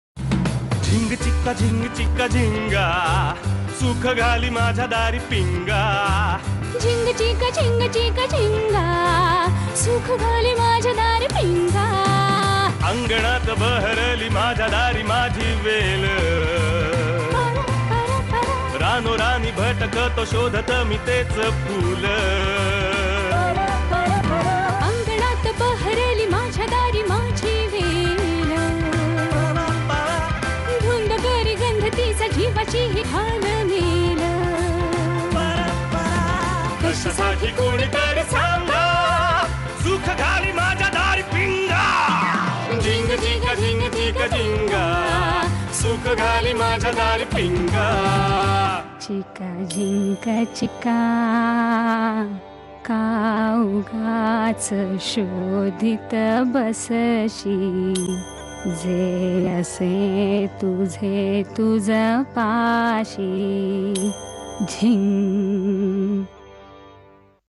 festive beats
Categories Indian Festival Ringtones